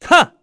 Esker-Vox_Attack1_kr.wav